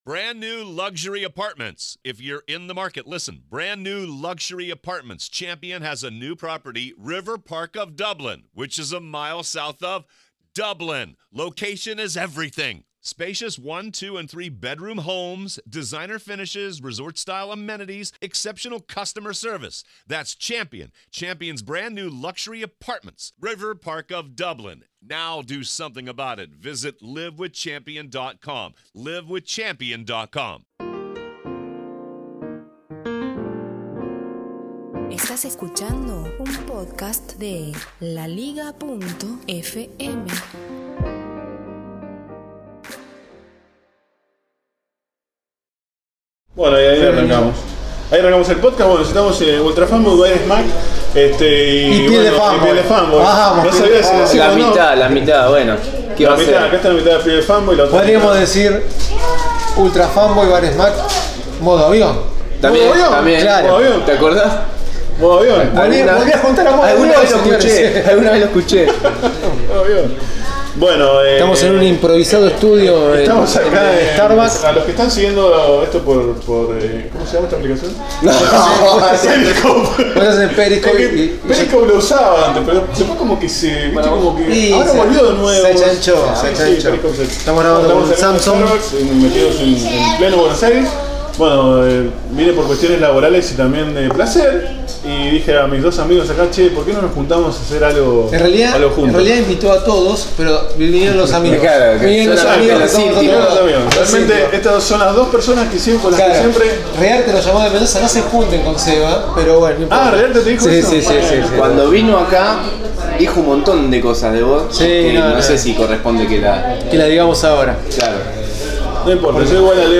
Juntada podcastera en Baires!
Podcast en Buenos Aires